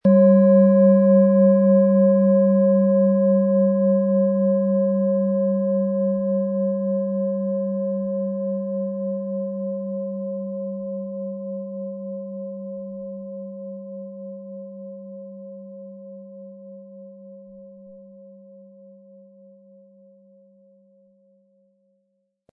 Planetenschale® Entspannt sein & Erdung mit OM-Ton & Tageston, Ø 16,4 cm, 500-600 Gramm inkl. Klöppel
• Tiefster Ton: Tageston
PlanetentöneOM Ton & Tageston & DNA (Höchster Ton)
MaterialBronze